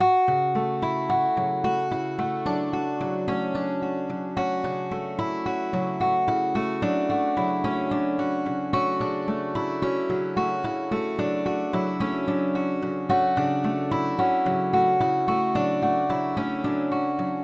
吉他键 110
Tag: 110 bpm Trap Loops Guitar Acoustic Loops 2.94 MB wav Key : Fm Cubase